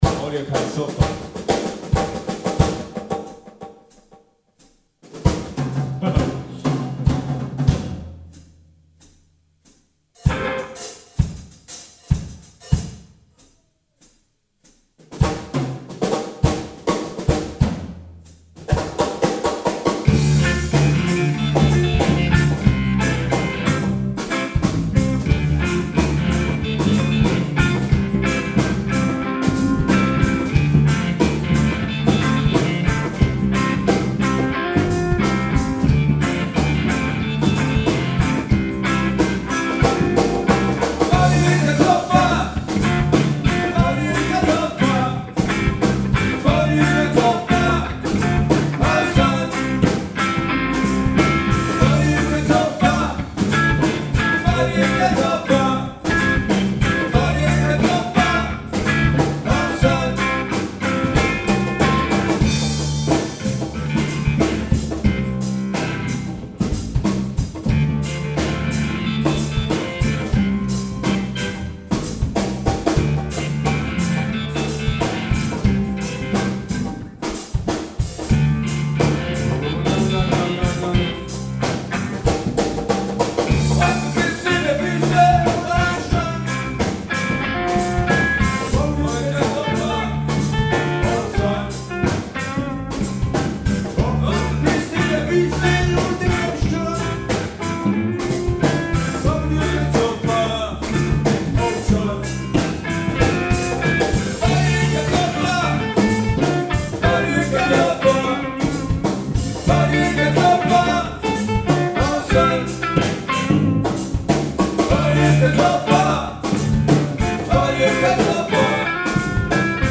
Tempo:90 bpm / Datum:  2013